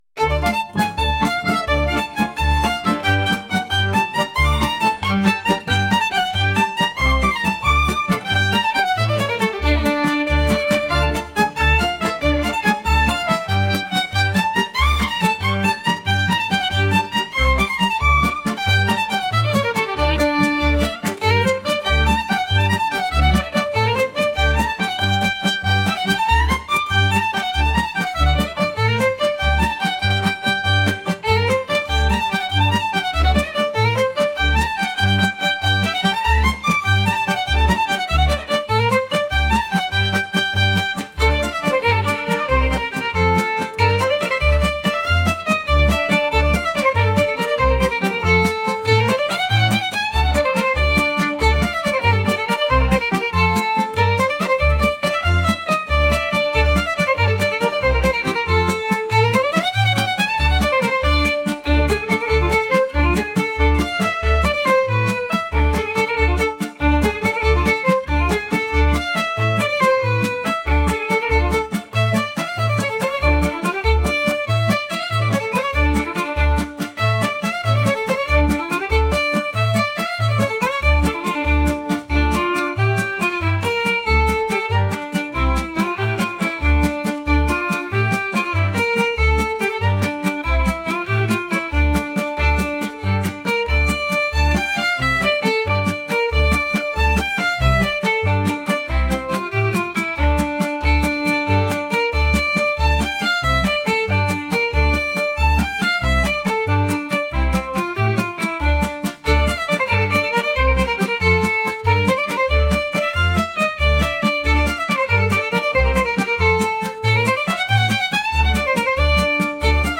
energetic | lively